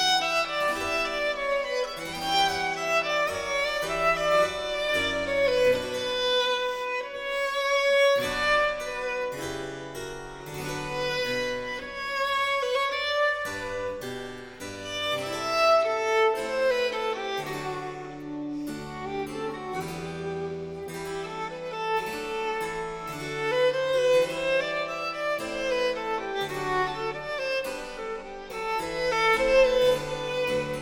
4 -- Sonata, fa mineur